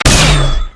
fire_tachyon4.wav